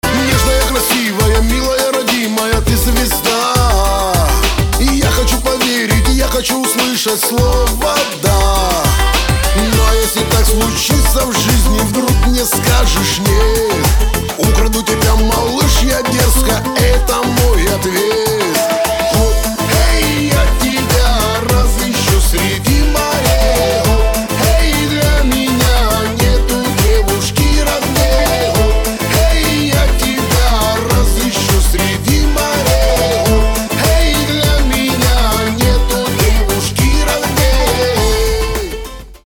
• Качество: 320, Stereo
мужской вокал
громкие
русский шансон